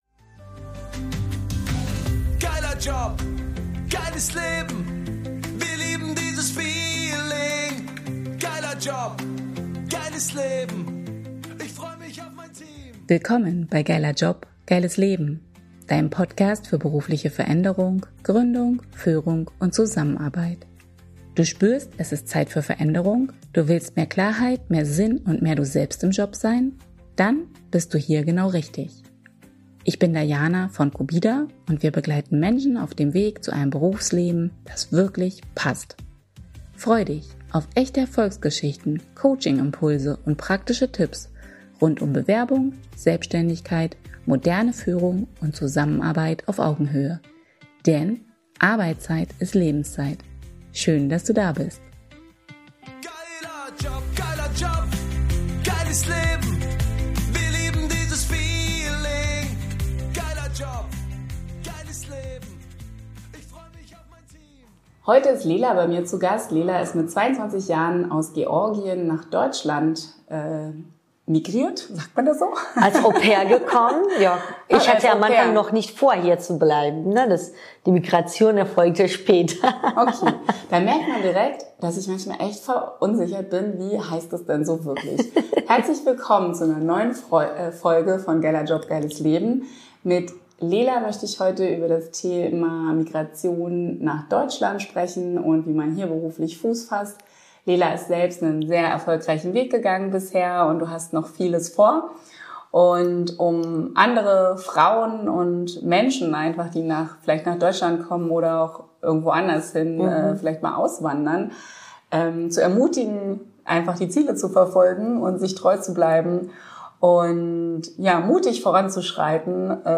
#021 Migration & Arbeitsmarkt: Wie Integration gelingt | Interview